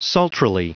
Prononciation du mot sultrily en anglais (fichier audio)
Prononciation du mot : sultrily